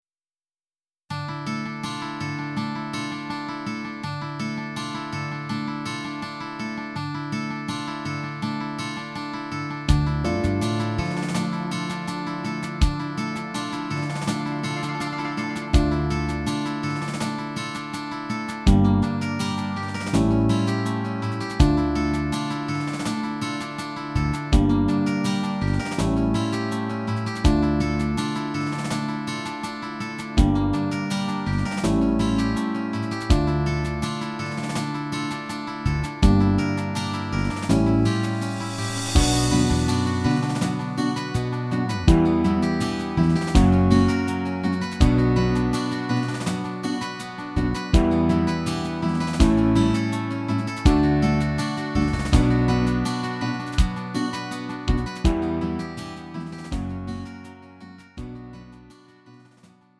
음정 원키 4:45
장르 가요 구분 Lite MR